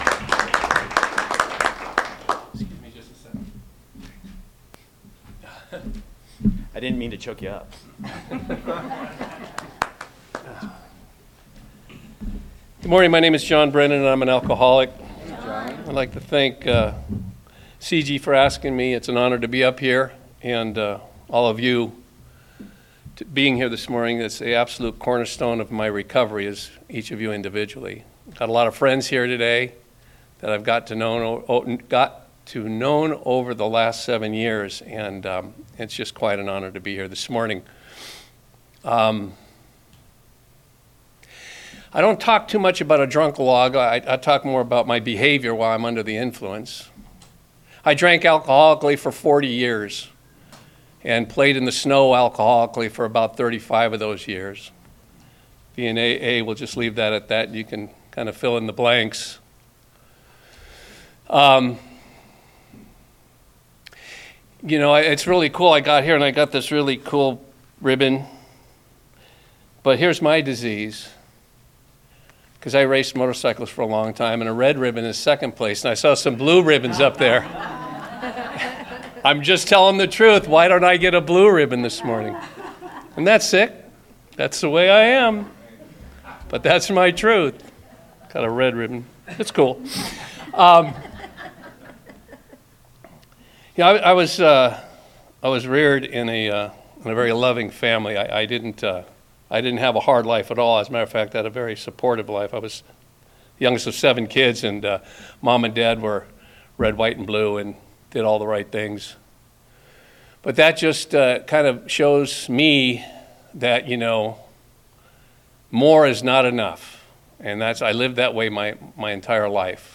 49th Annual Antelope Valley Roundup - Palmdale